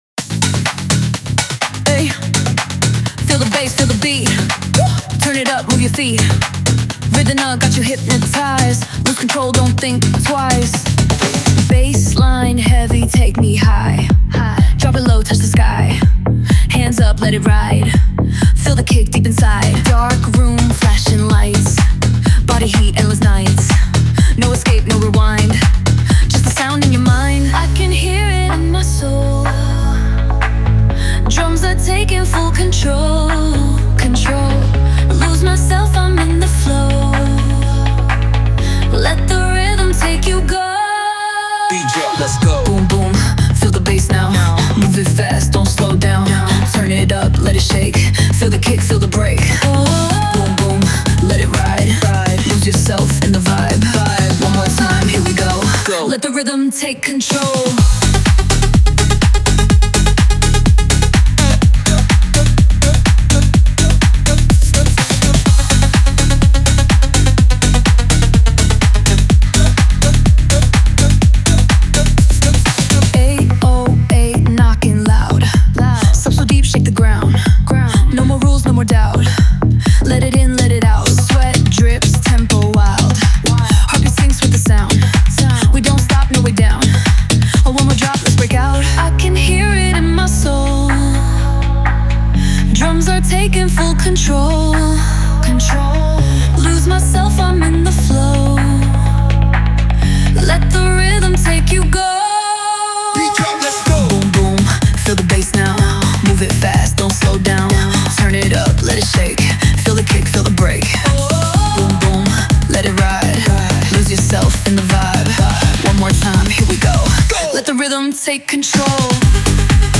Tech House | Percussive House | Minimal Groove | Club Banger